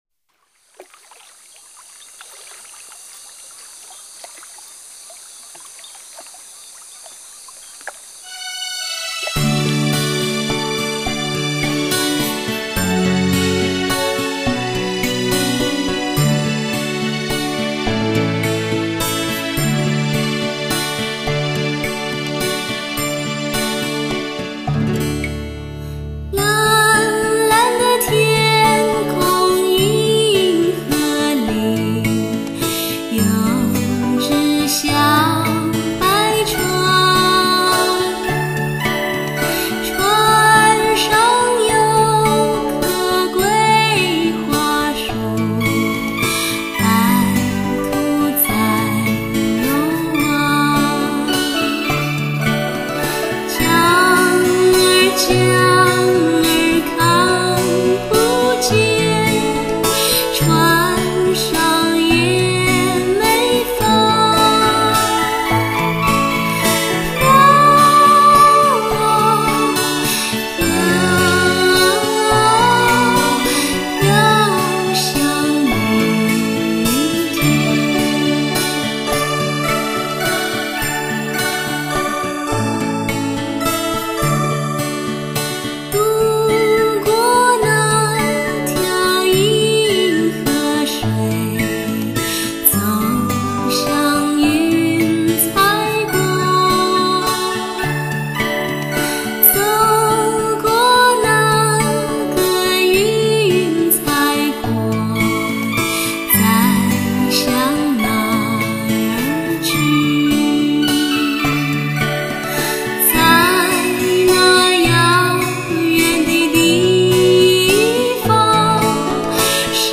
环绕音乐试听碟